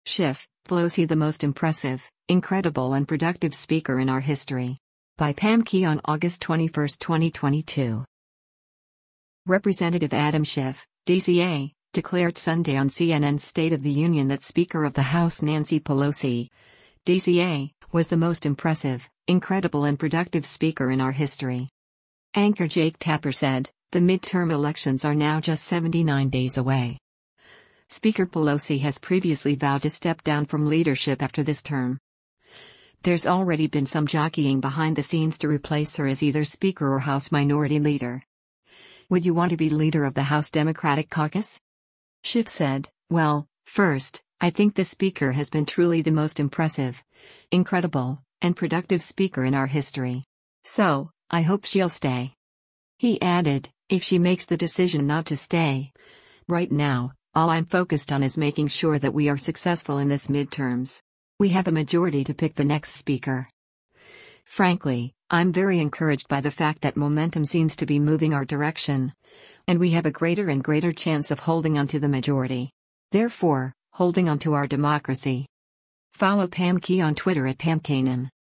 Representative Adam Schiff (D-CA) declared Sunday on CNN’s “State of the Union” that Speaker of the House Nancy Pelosi (D-CA) was the “most impressive, incredible and productive speaker in our history.”